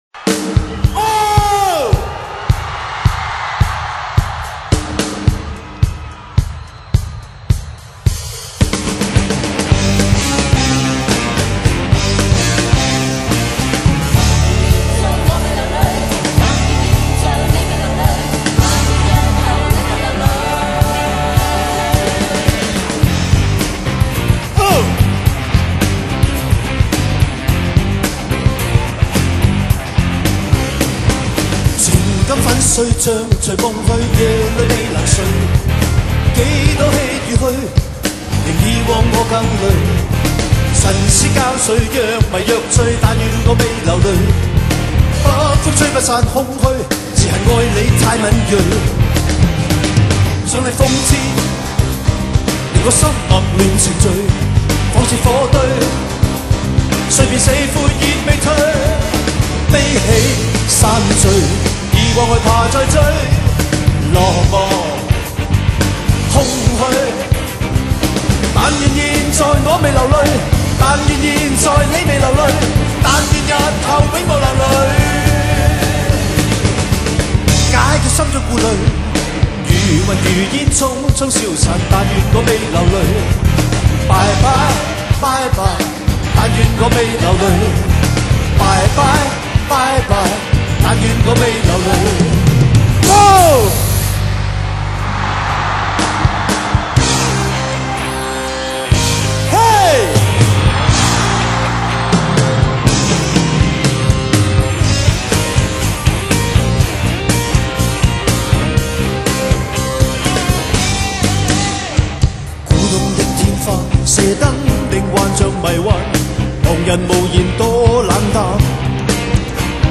Rock Medley